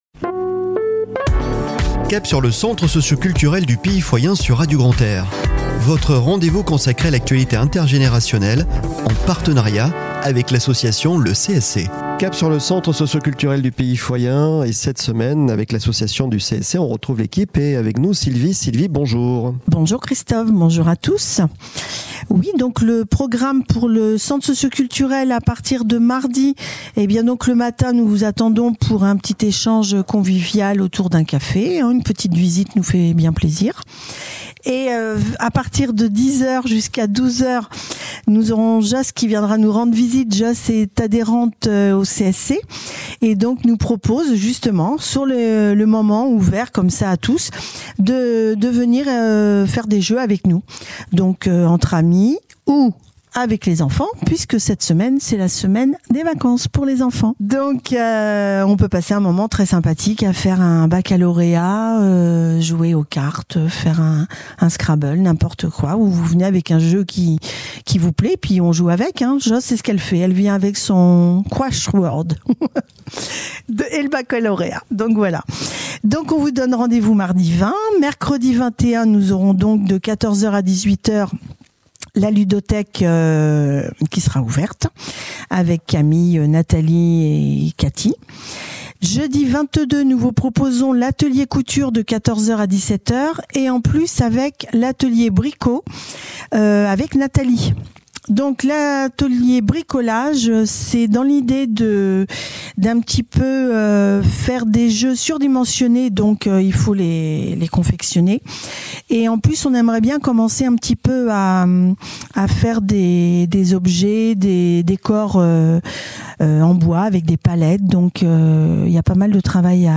Chronique de la semaine du 19 au 25 Février 2024 !